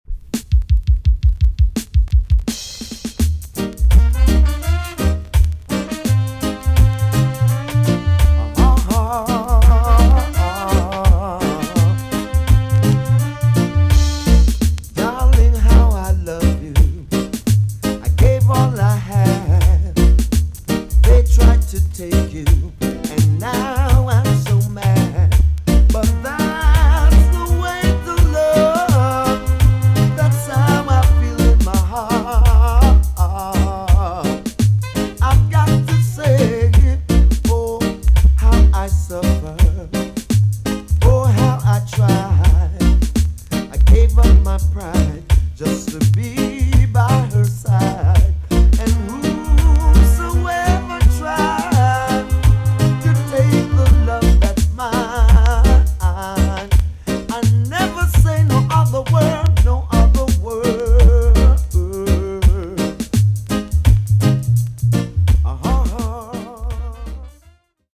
TOP >DISCO45 >80'S 90'S DANCEHALL
B.SIDE Version
EX- 音はキレイです。